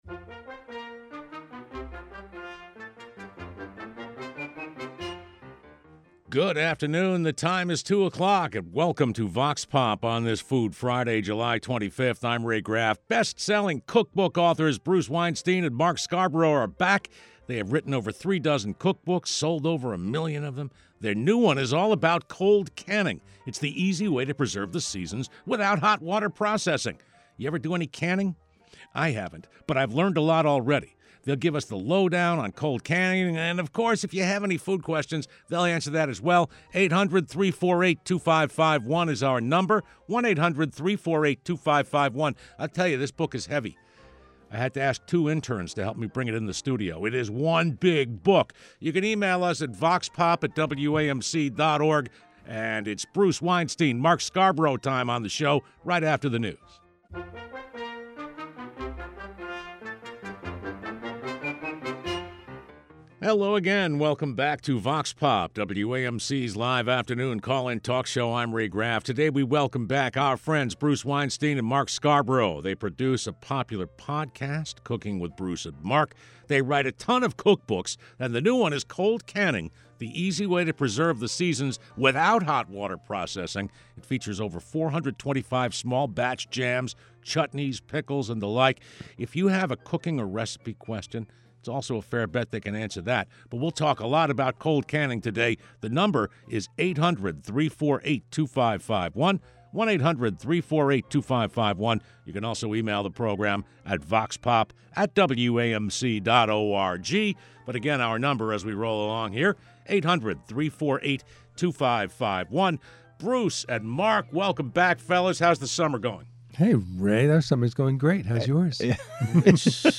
Vox Pop is WAMC's live call-in talk program.
Our experts take questions posed by WAMC listeners.